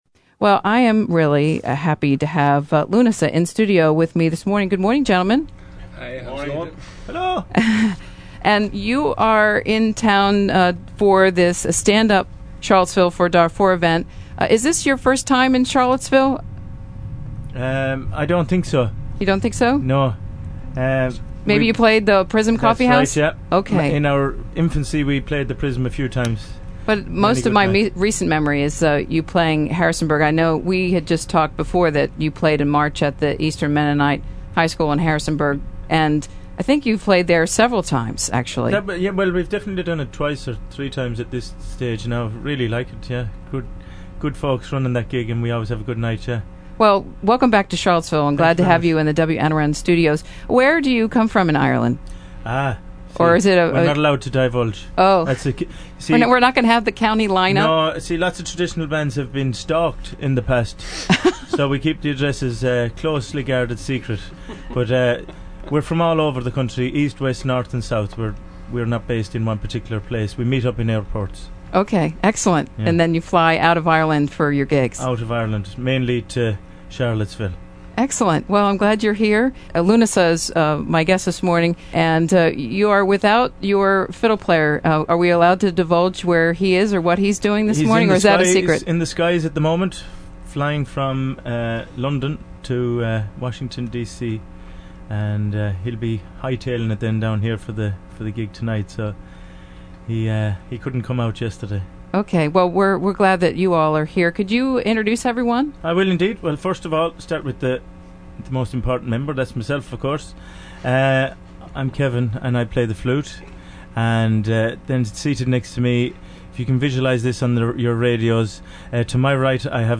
They played a set of jigs and a set of reels.